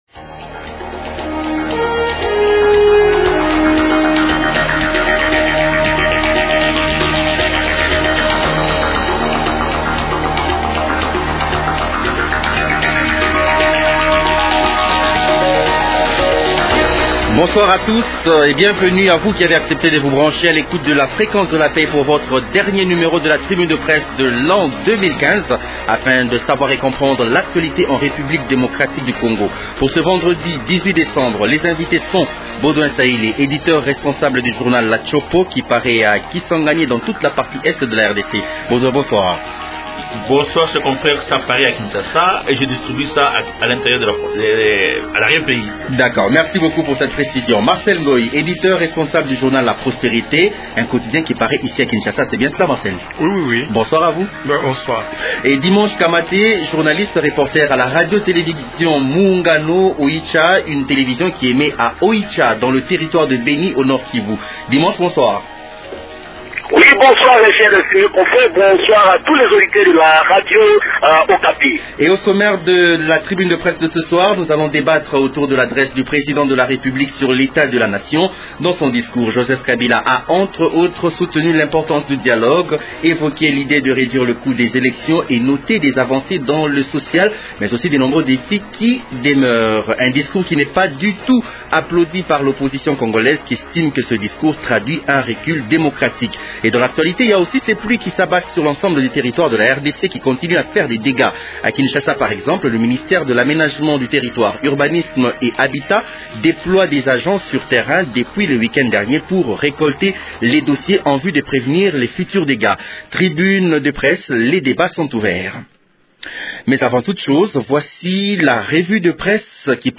Discours du président de la République devant le congrès sur l’état de la Nation